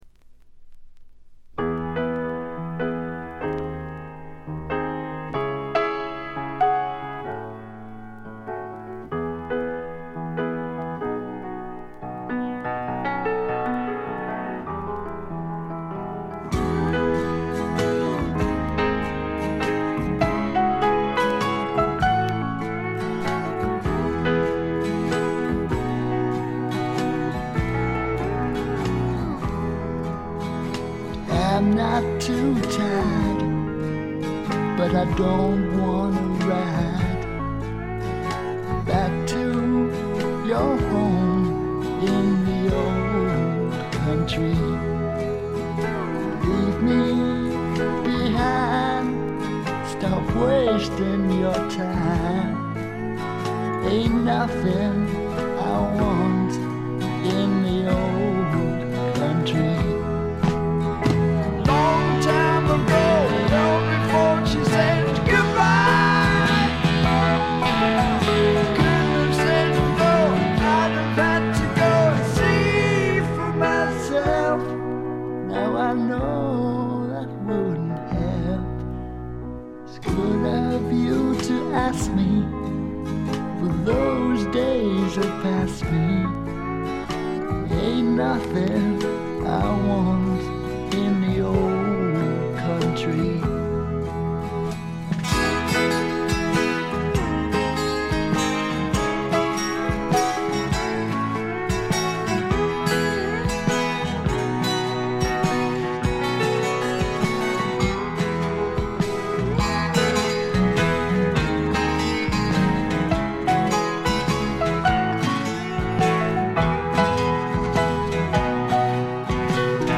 ところどころで軽いチリプチ。
絞り出すような味のある渋いヴォーカルが何と言っても彼の最大の持ち味。
試聴曲は現品からの取り込み音源です。